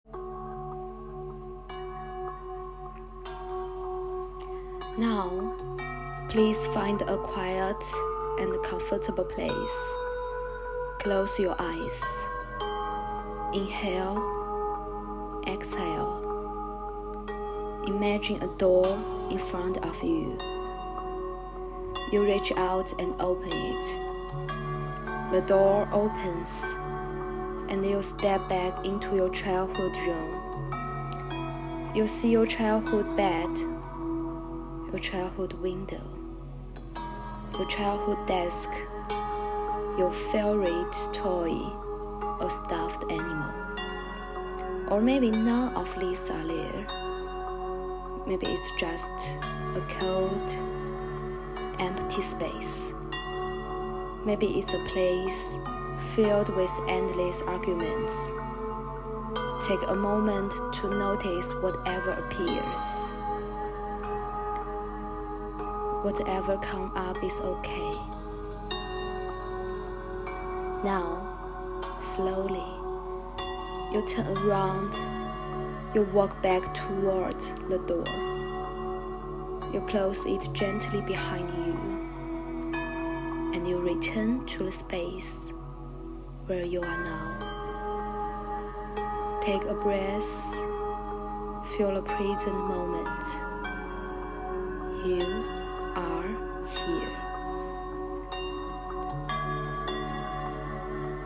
Step 1: Guided Sensory Recall (5 minutes)
Meditation-Enter-your-room.mp3